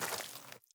added stepping sounds
Mud_Mono_03.wav